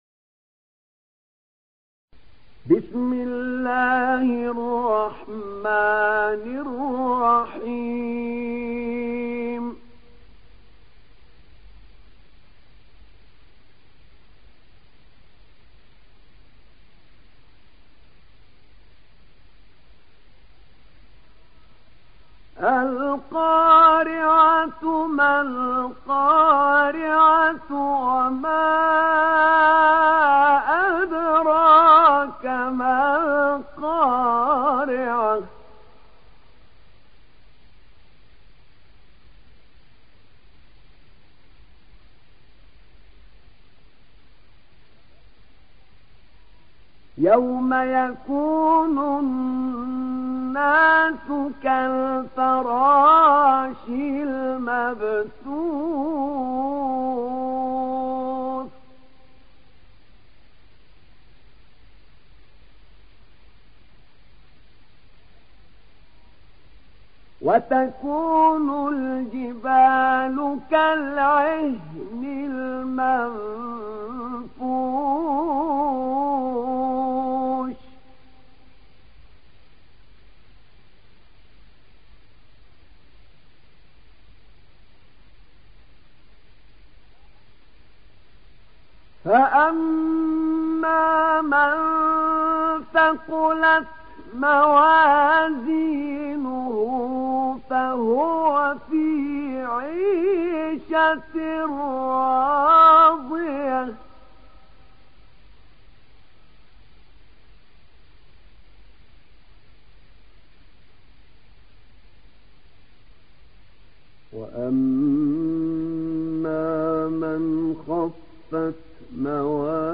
تحميل سورة القارعة mp3 بصوت أحمد نعينع برواية حفص عن عاصم, تحميل استماع القرآن الكريم على الجوال mp3 كاملا بروابط مباشرة وسريعة